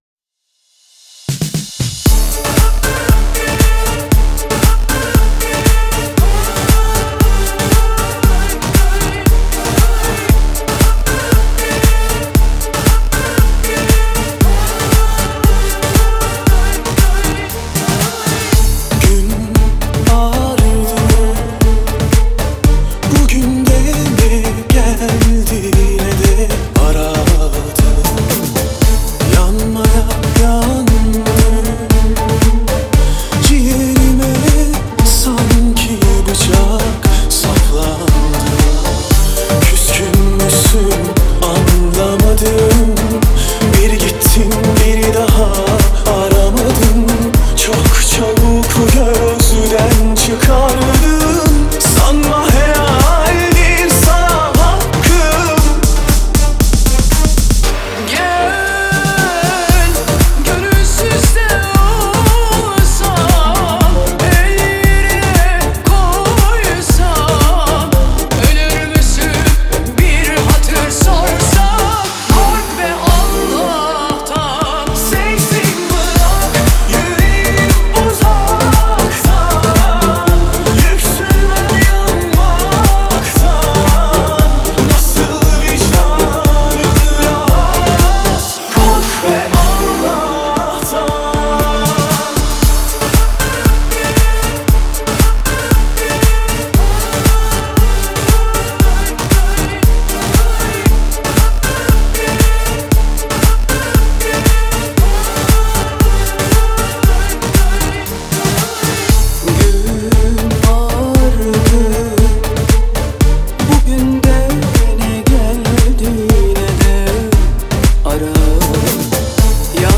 دانلود نسخه ریمیکس همین موزیک